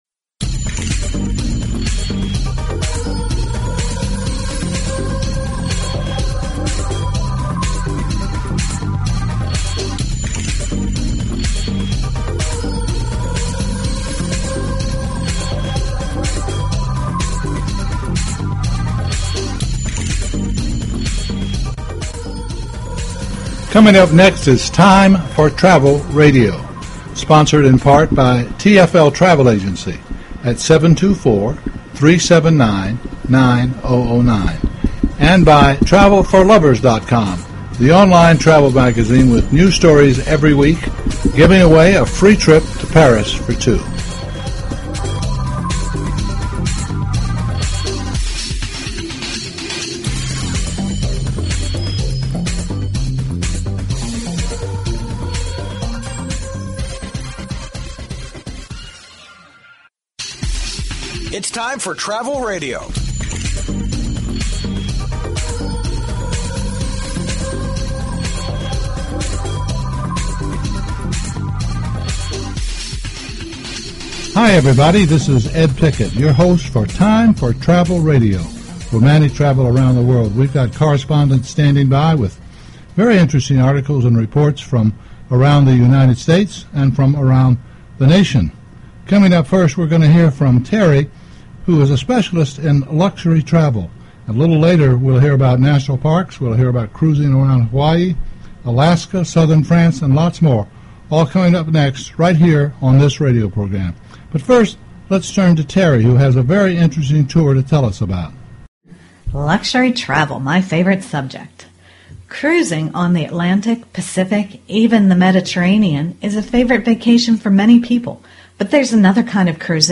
Talk Show Episode, Audio Podcast, Time_for_Travel_Radio and Courtesy of BBS Radio on , show guests , about , categorized as